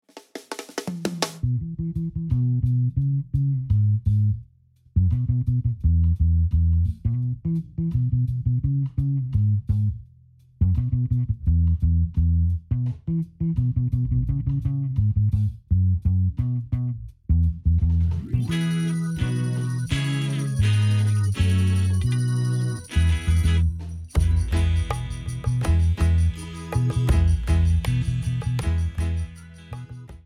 A play-along track in the style of reggae, ska.